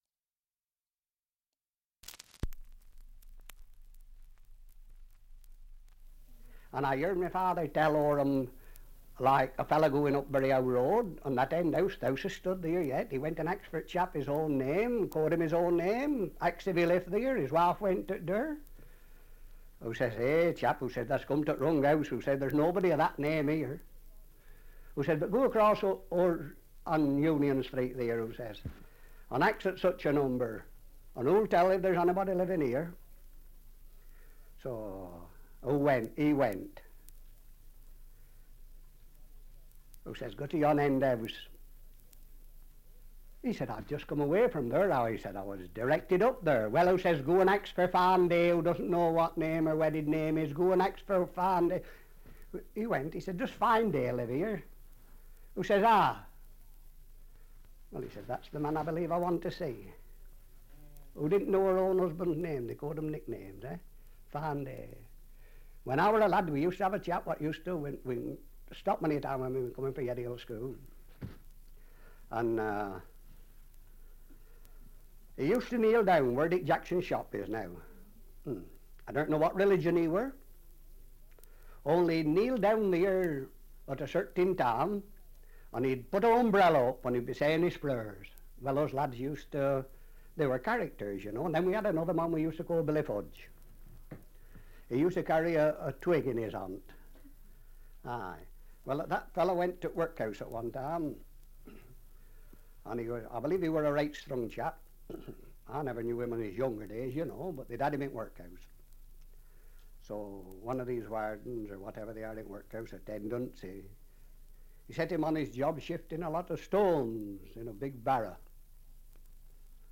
2 - Dialect recording in Heywood, Lancashire
78 r.p.m., cellulose nitrate on aluminium